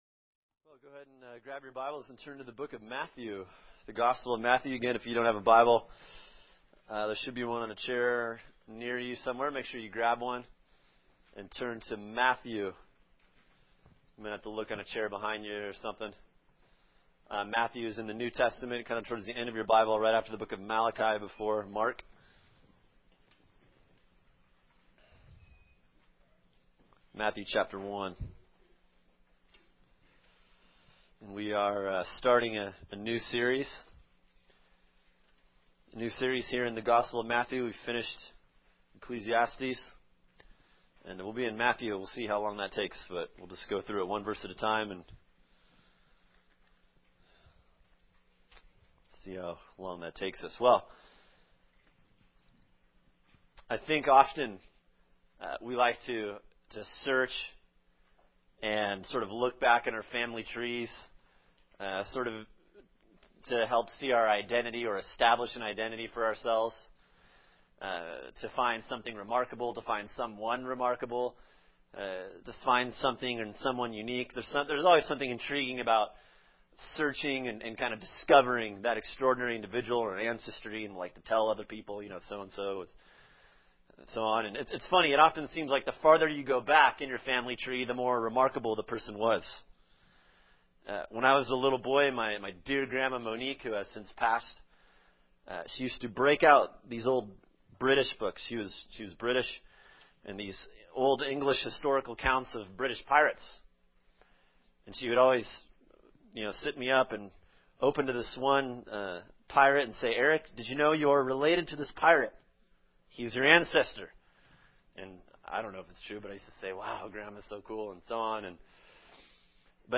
[sermon] Matthew 1:1-17 “Unlikely Royalty” | Cornerstone Church - Jackson Hole